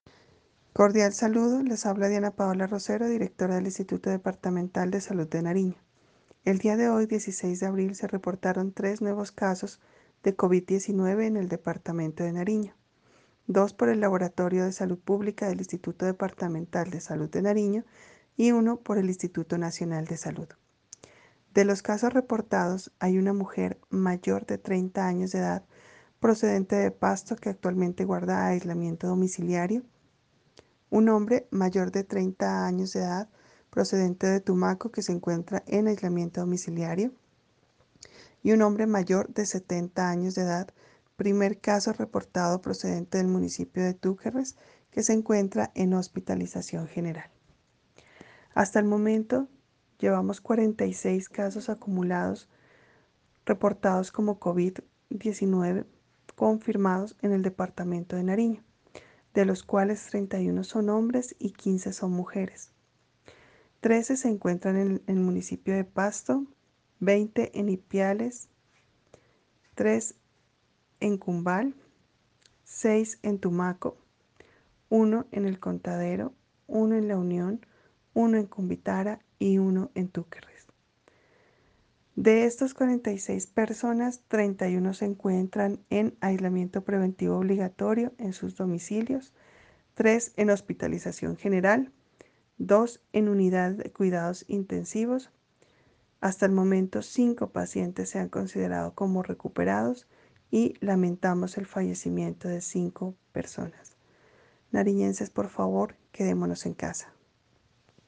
Declaración de la directora del IDSN Diana Paola Rosero